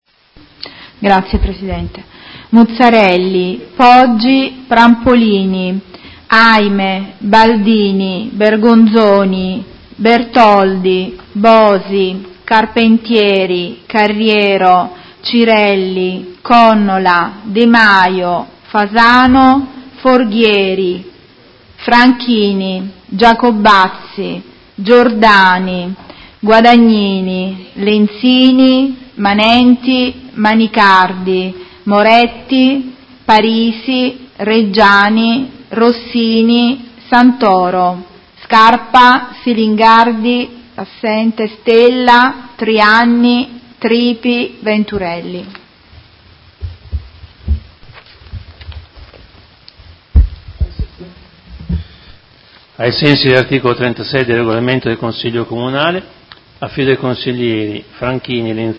Segretaria — Sito Audio Consiglio Comunale
Seduta del 19/09/2019 Appello.